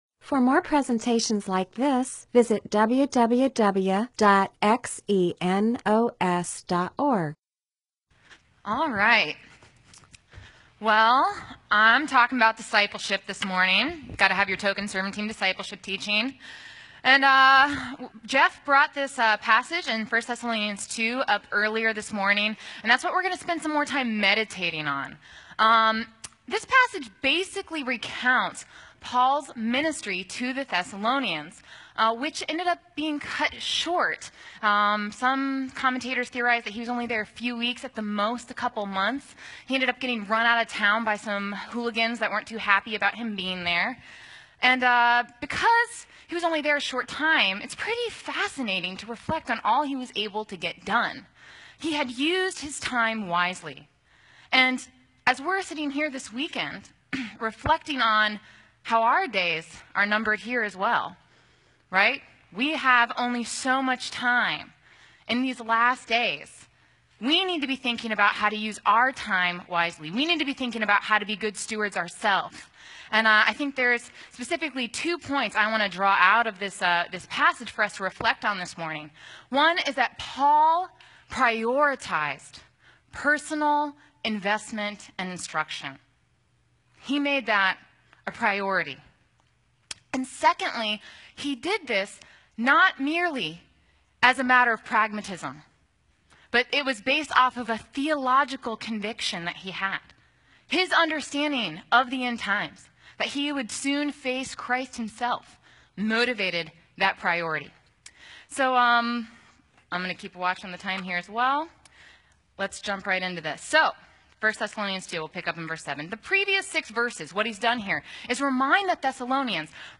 MP4/M4A audio recording of a Bible teaching/sermon/presentation about 1 Thessalonians 2:7-20.